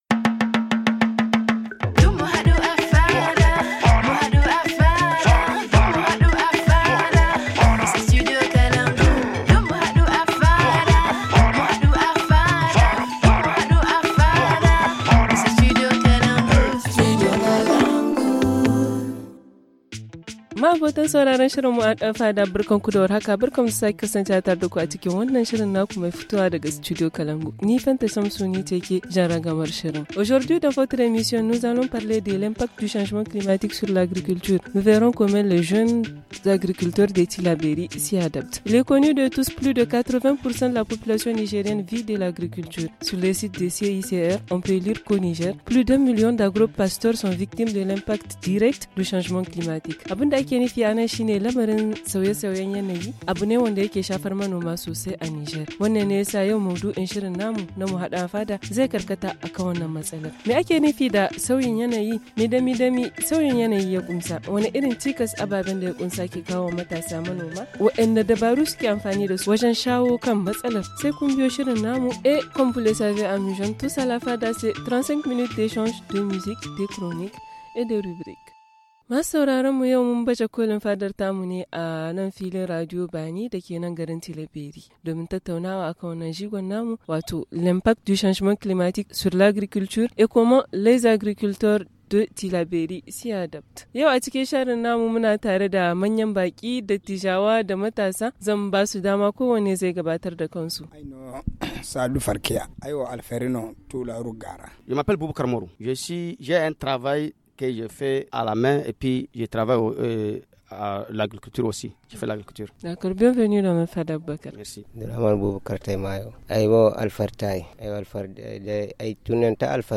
Nous sommes à la radio Bani de Tillabéry une radio partenaire du studio kalangou avec nous : –